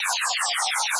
Category 🎮 Gaming
arcade effect game image space synth sound effect free sound royalty free Gaming